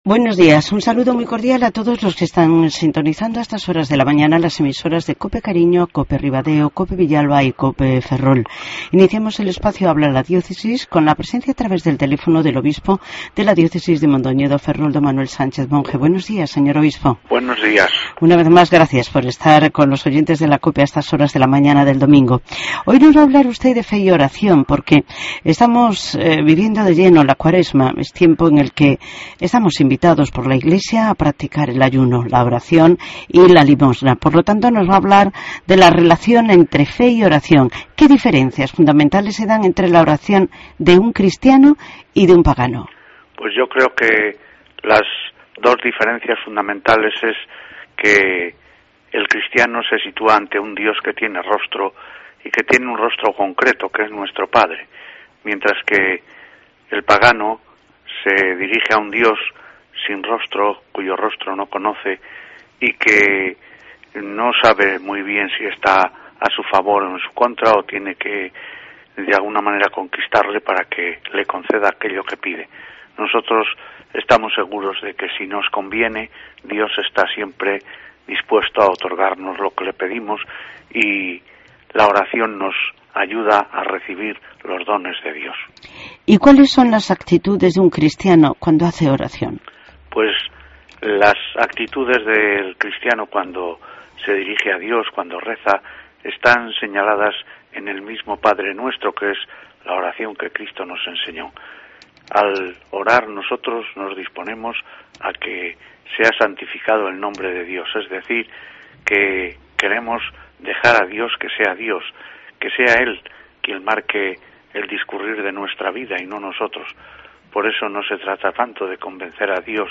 AUDIO: Mons. Sánchez Monge nos habla de la relación entre la fe y la oración, en este tiempo de la Cuaresma.